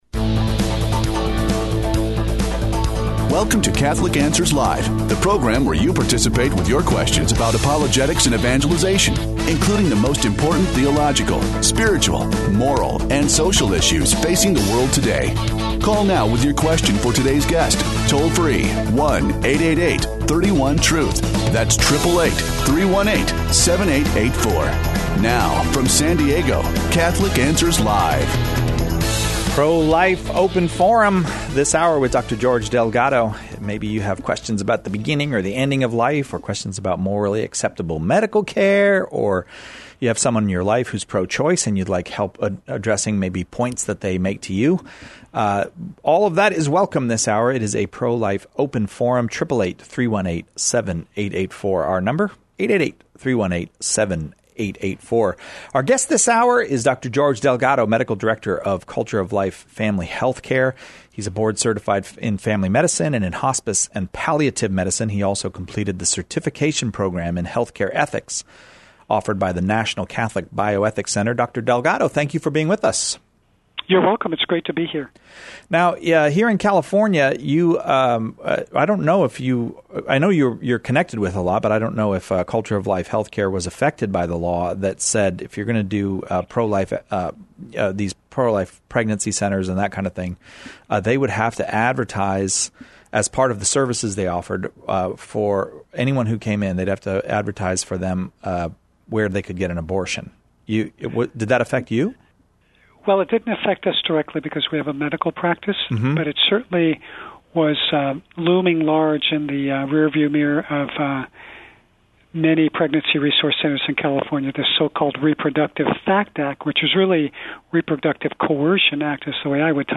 takes listener calls about the beginning and end of life and about the proper use of medical technology.